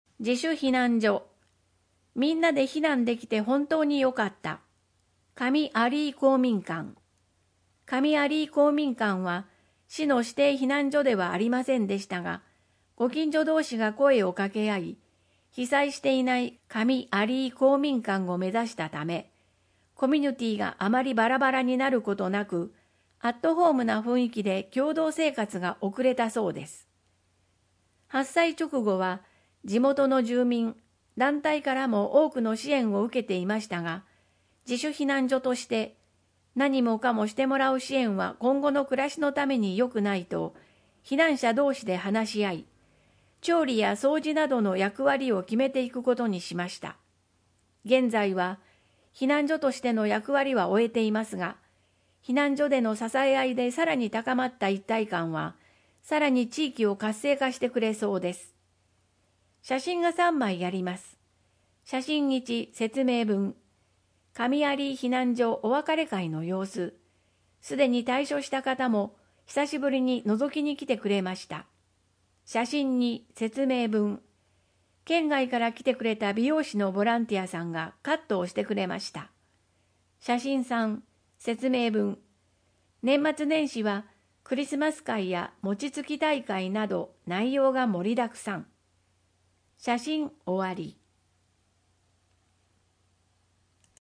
豪雨ニモマケズ（音訳版）